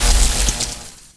jess_hit_01.wav